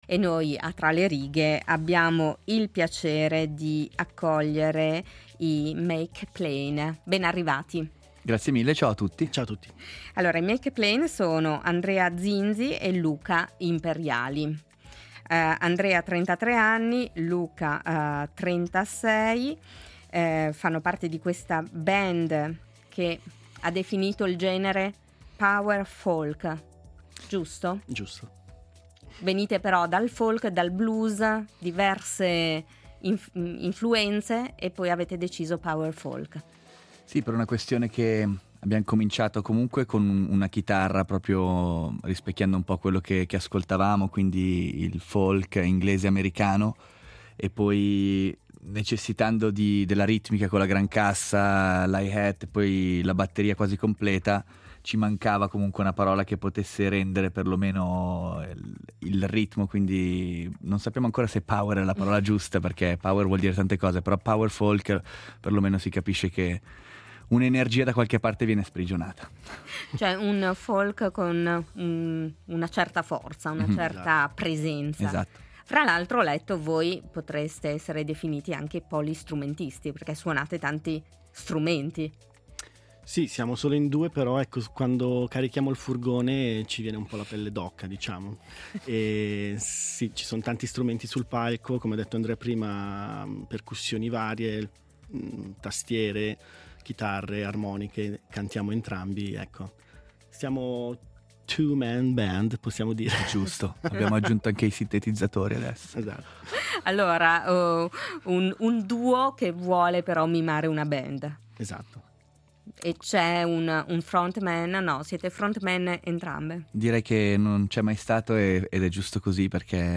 duo “Power Folk”